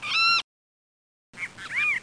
00017_Sound_seagulls.mp3